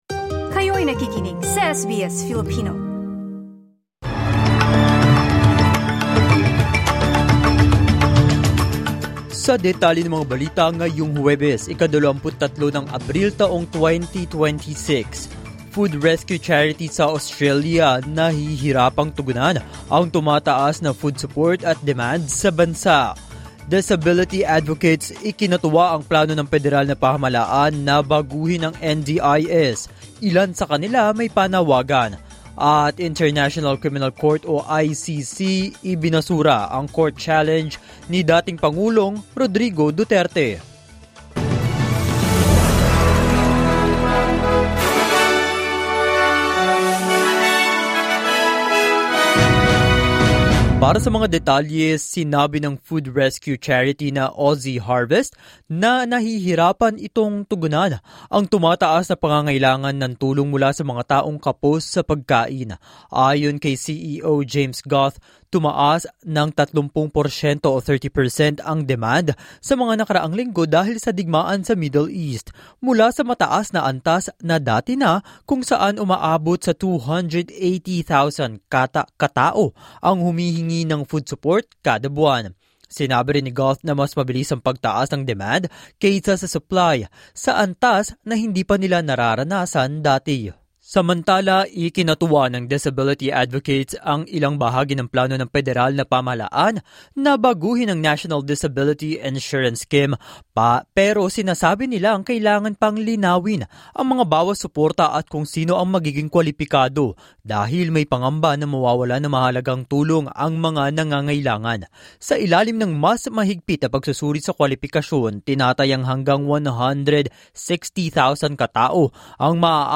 SBS News in Filipino, Thursday 23 April 2026